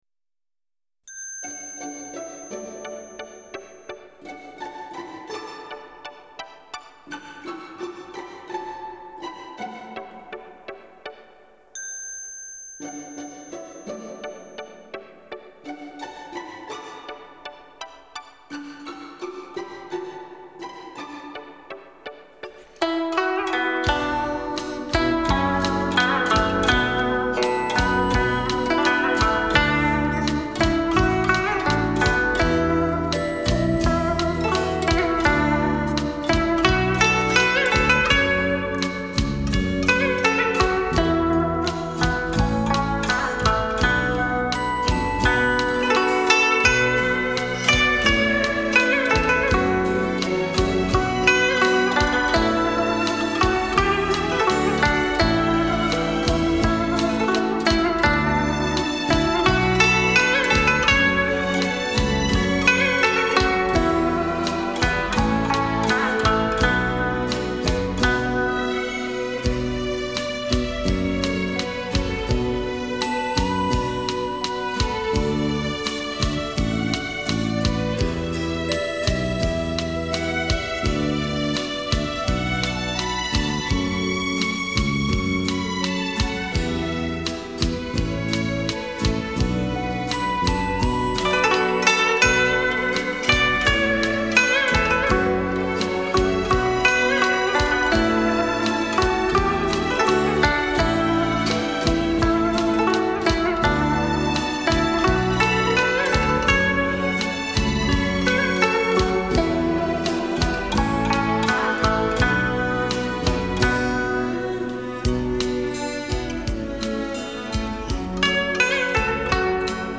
古筝独奏